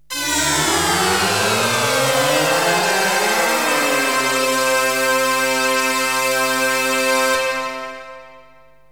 SYNTH GENERAL-1 0005.wav